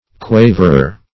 Quaverer \Qua"ver*er\, n. One who quavers; a warbler.